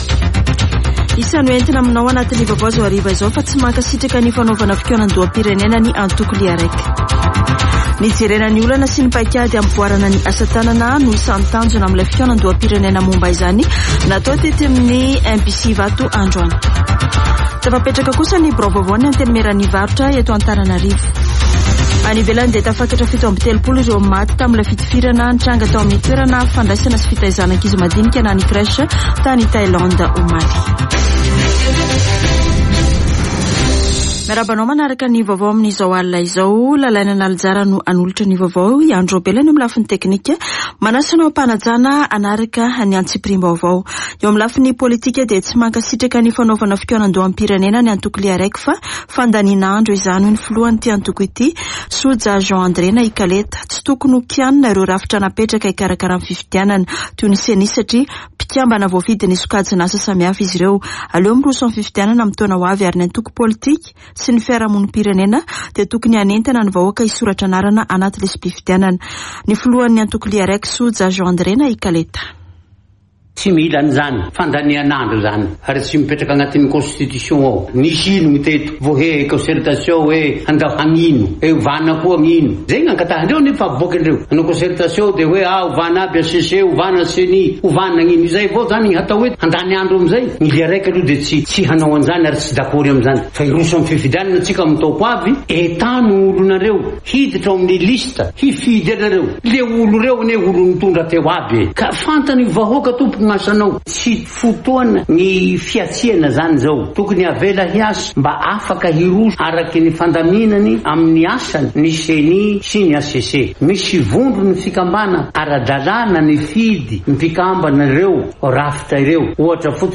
[Vaovao hariva] Zoma 7 ôktôbra 2022